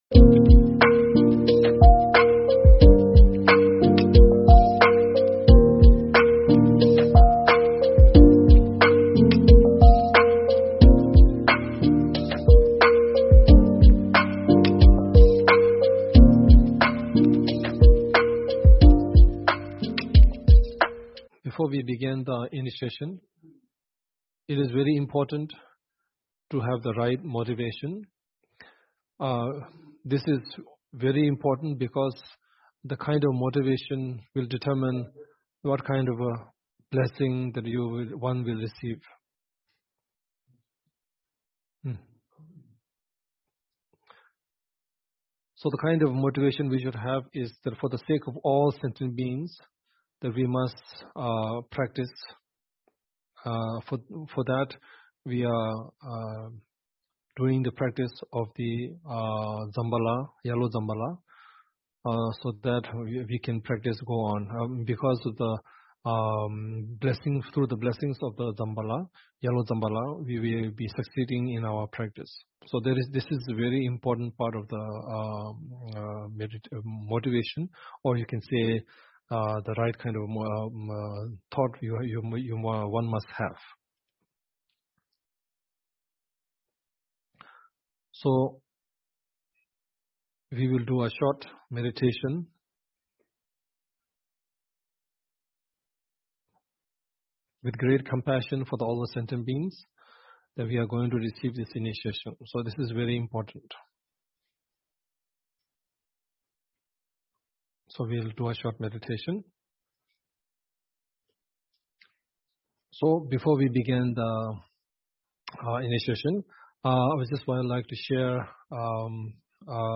Topic: Advice Before and After a Jambhala Initiation Author: H.H. the 43rd Sakya Trizin Venue: Online Video and Audio Source: Taipei Vajrayana Sakya Manjushri Center